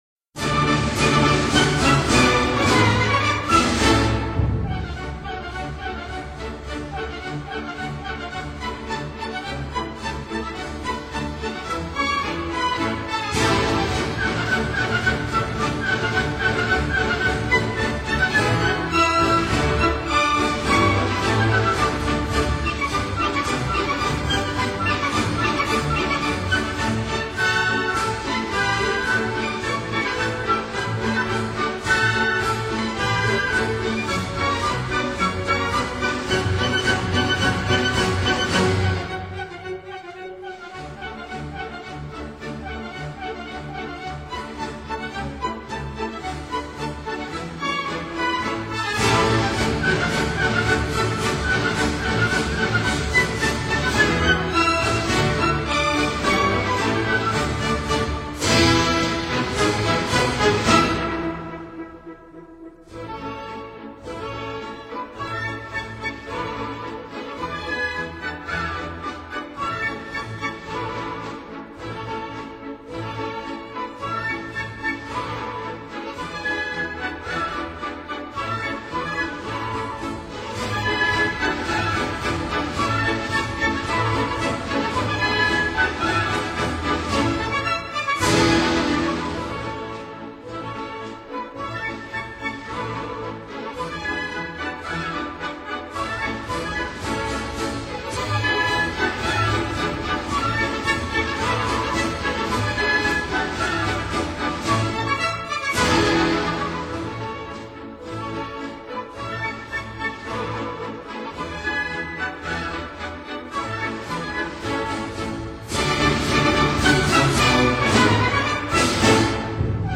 Radetzky March (marssipoloneesi)